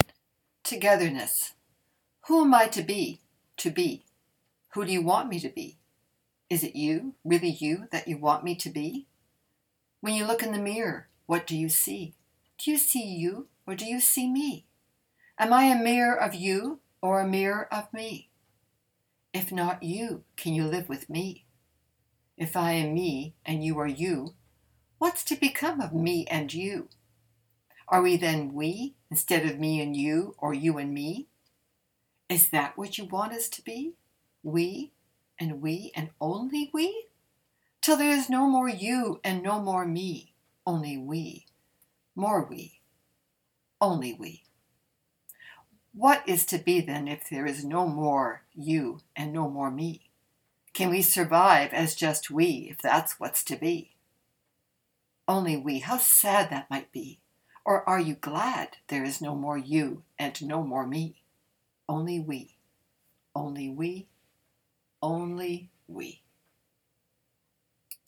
Brian Brett — a Canadian poet who won the CBC literary prize for poetry one year — recommended the poem be read aloud to get its full intent.